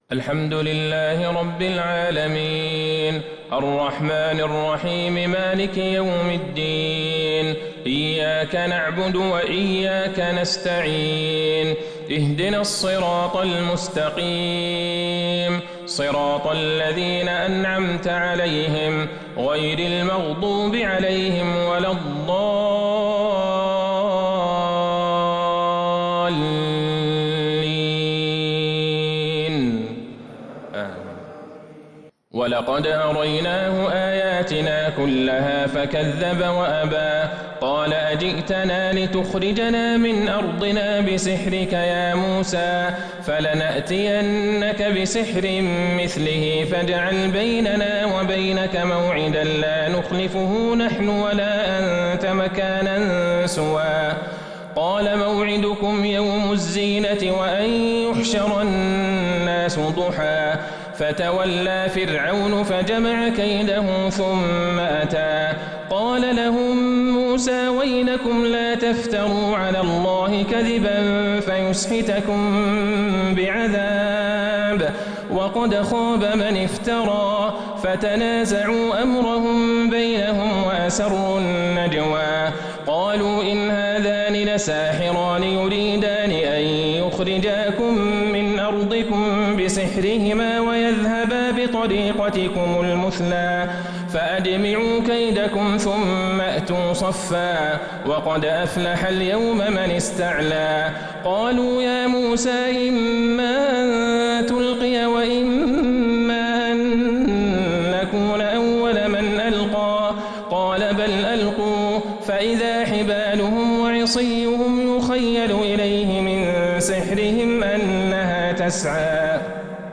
صلاة العشاء للقارئ عبدالله البعيجان 8 ربيع الآخر 1442 هـ